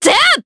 Nicky-Vox_Attack2_jp.wav